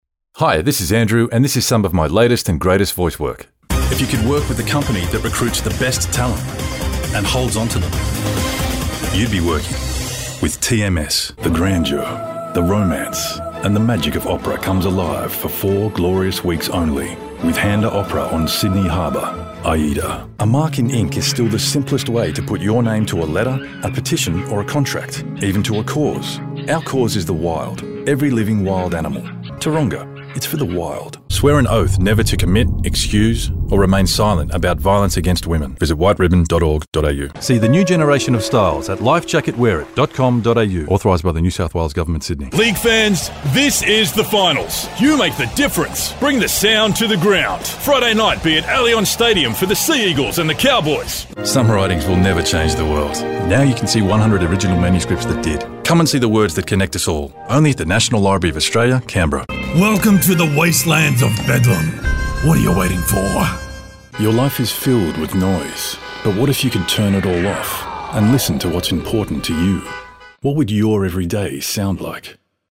Inglês (australiano)
Amigáveis
Escuro